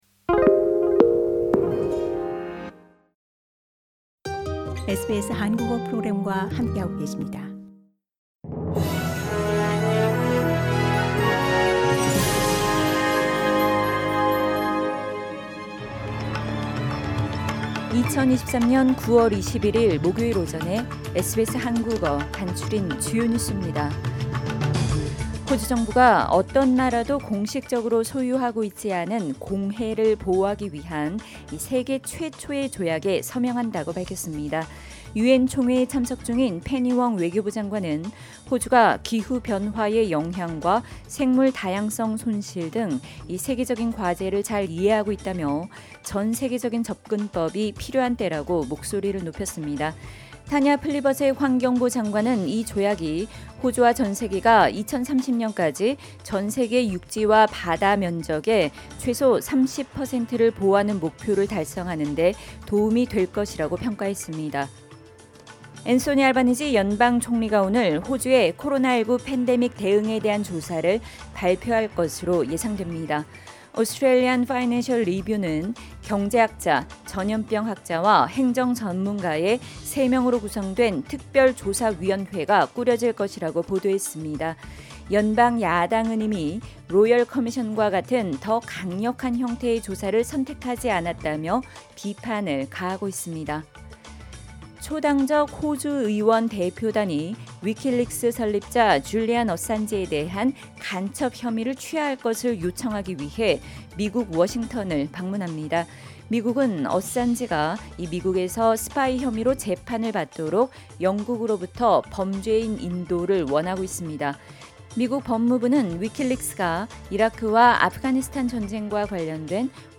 2023년 9월21일 목요일 아침 SBS 한국어 간추린 주요 뉴스입니다.